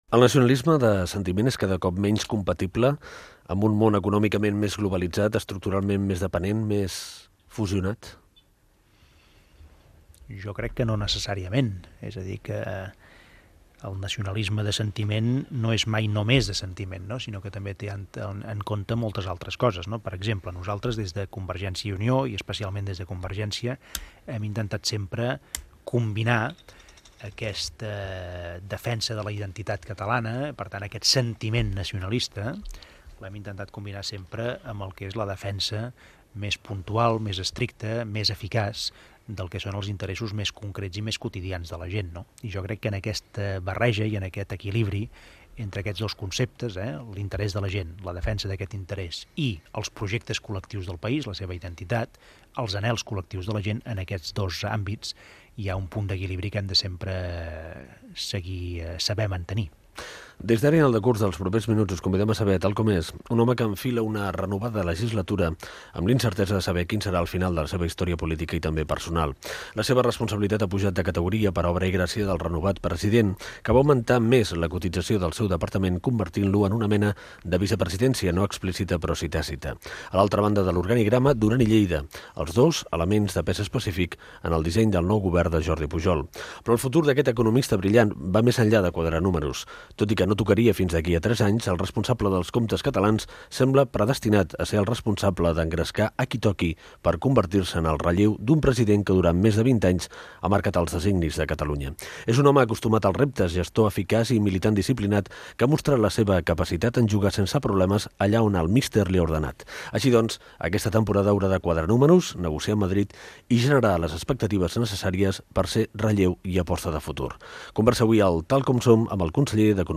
Fragment extret de l'arxiu sonor de COM Ràdio.